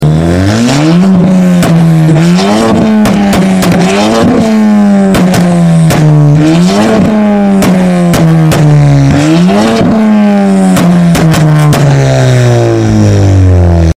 Straight Piped Fiesta ST Spitting